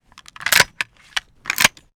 machinegun_reload_02.wav